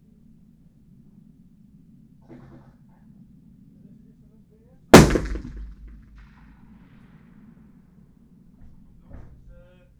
01_gunshot/shot556_71_ch01_180718_162953_00_.wav · UrbanSounds/UrbanSoundsNew at main
Environmental
Streetsounds
Noisepollution